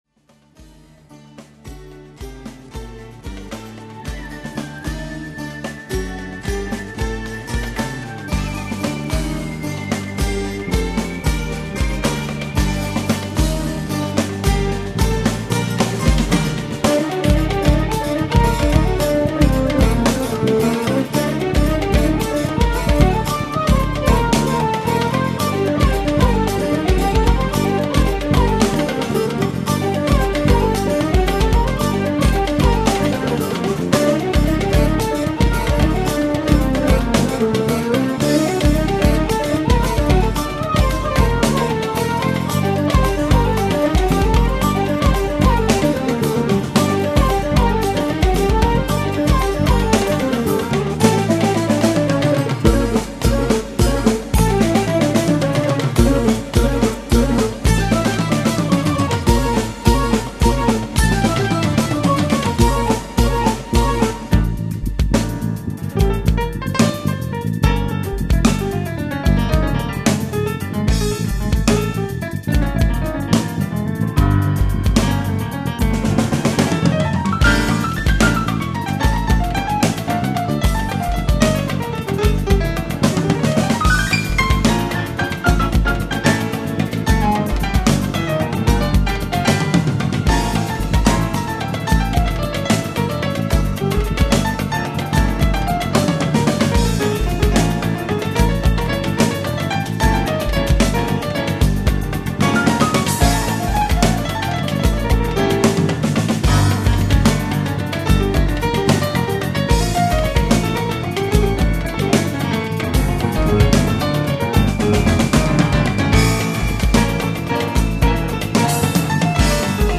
Of course, my main instrument is piano.
:) Here, it's XP80 and Wavestation layered: